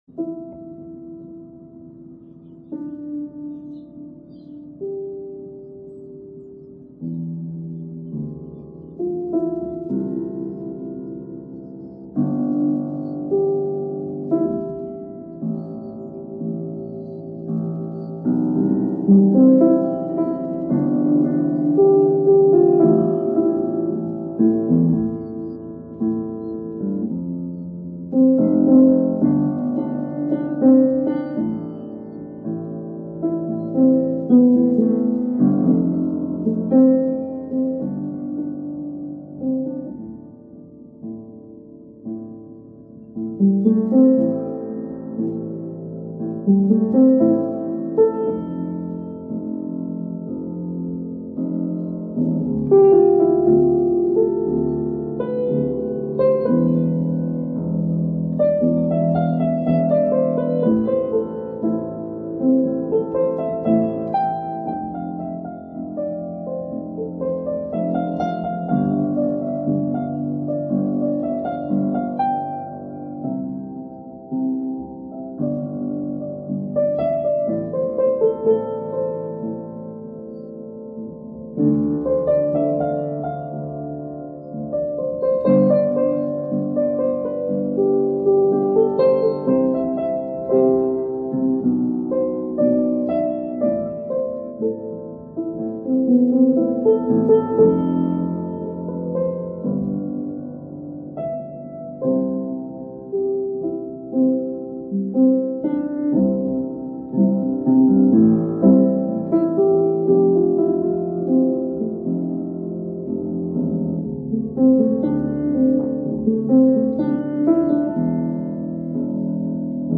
Piano score